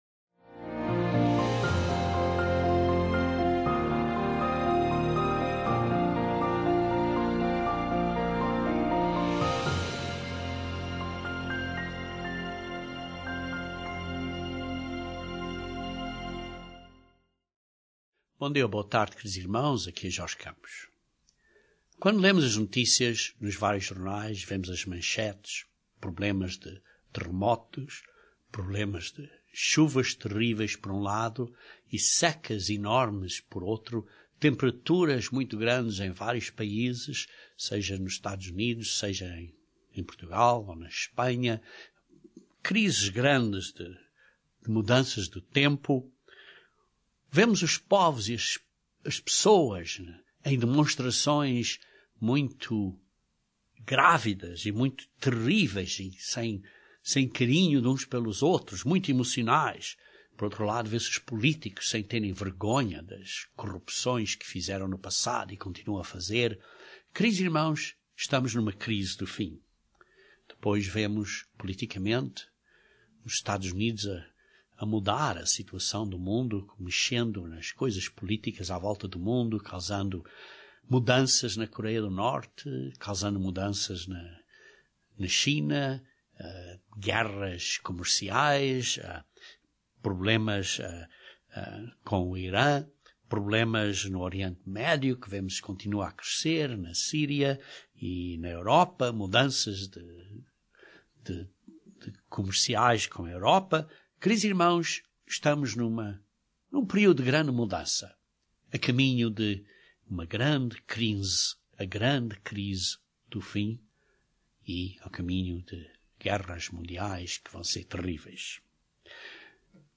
Antes de Jesus intervir, nós temos que passar por tempos muito difícies. Este sermão é uma sinópse breve dos acontecimentos profetizados, antes da vida de Cristo. Entre esses acontecimentos, Deus promete uma proteção de Seus chamados e eleitos.